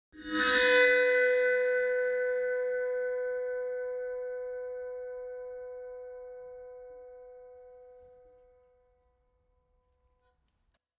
AV_Sword_FX
AV_Sword_FX.wav